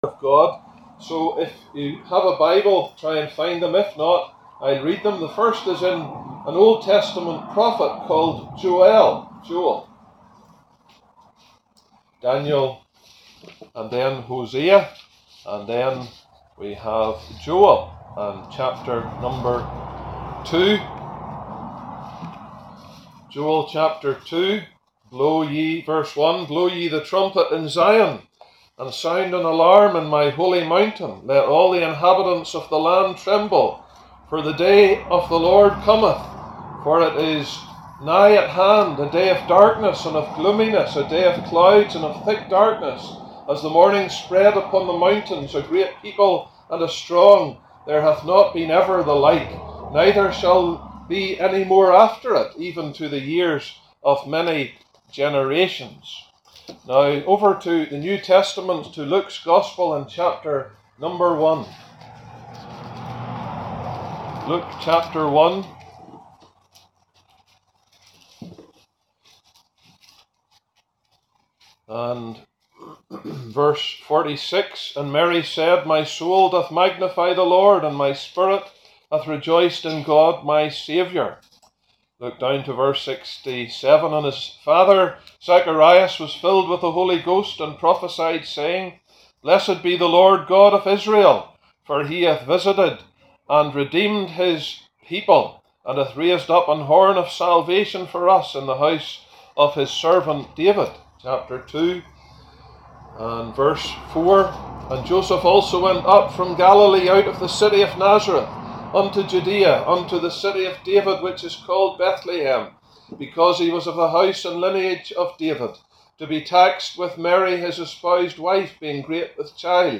preaches the gospel using Israel as a reference point.